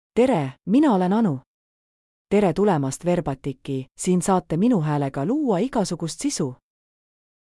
Anu — Female Estonian (Estonia) AI Voice | TTS, Voice Cloning & Video | Verbatik AI
Anu is a female AI voice for Estonian (Estonia).
Voice sample
Female